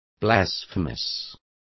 Complete with pronunciation of the translation of blasphemous.